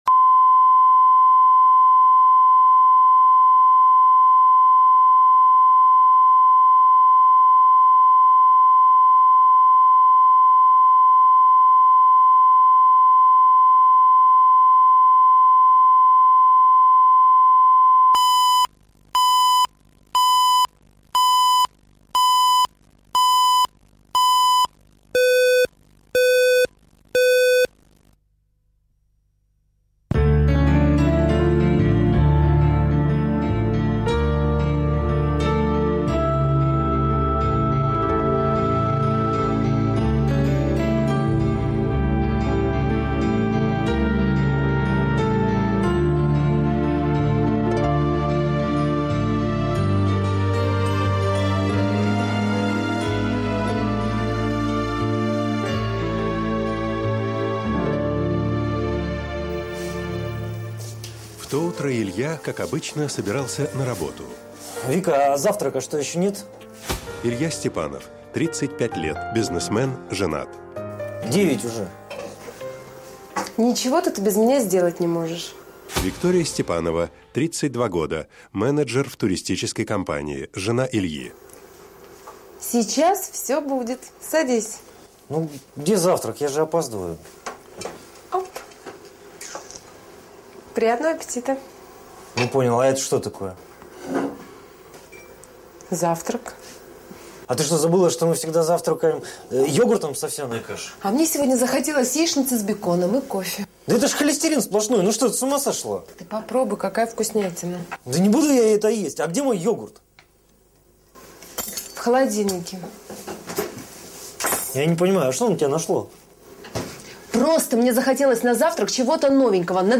Аудиокнига Идеальный брак | Библиотека аудиокниг